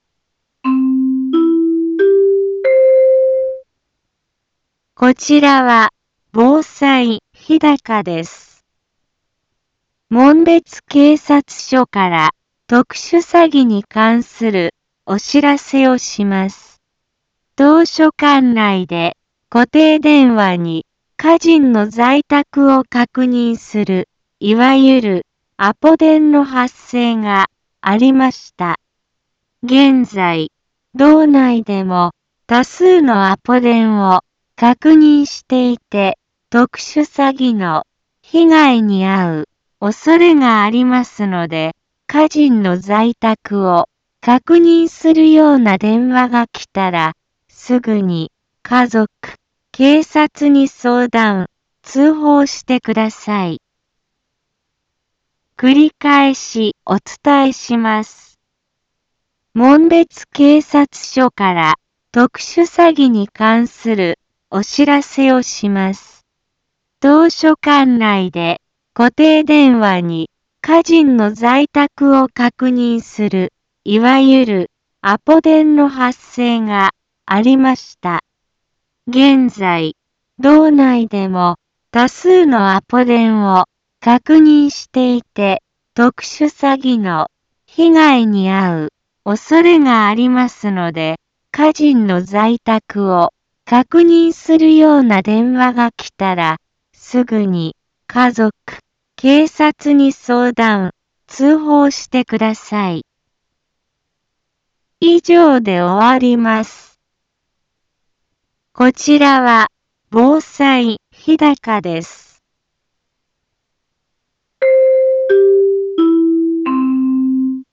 Back Home 一般放送情報 音声放送 再生 一般放送情報 登録日時：2023-02-27 15:04:08 タイトル：特殊詐欺被害防止に関するお知らせ インフォメーション：こちらは防災日高です。 門別警察署から特殊詐欺に関するお知らせをします。